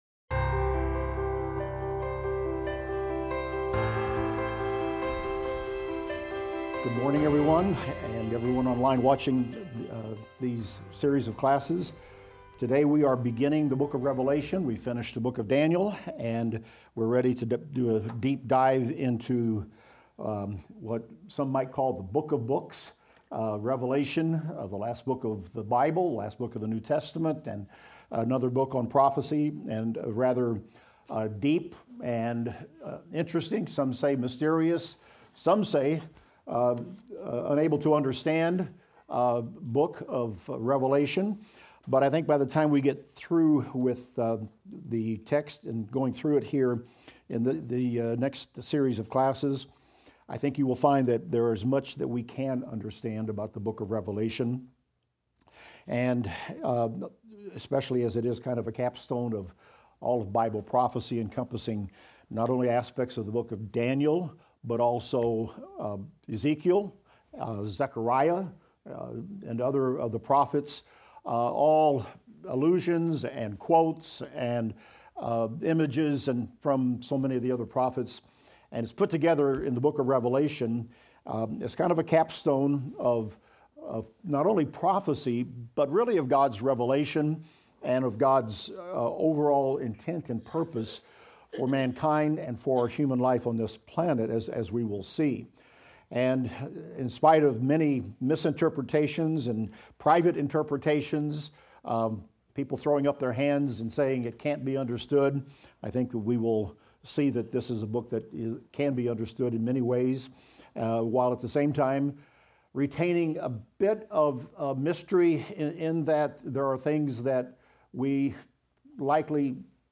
Revelation - Lecture 25 - Audio.mp3